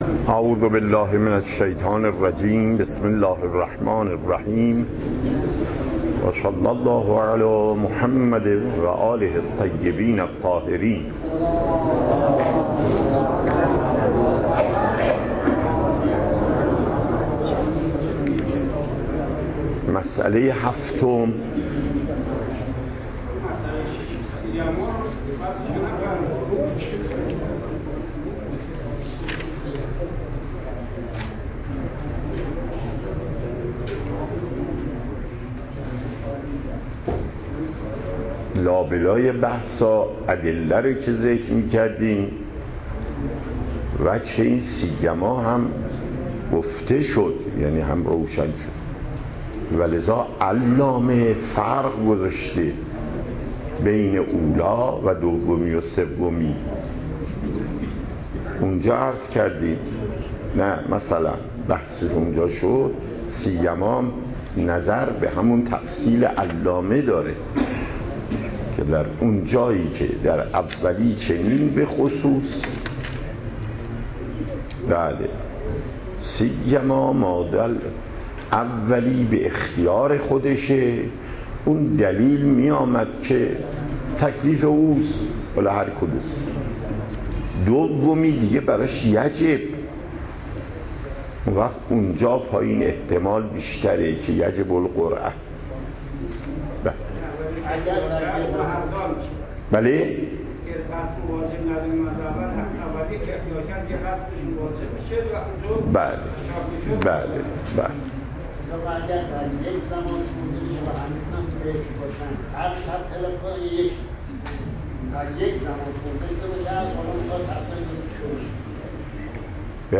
پخش صوت درس: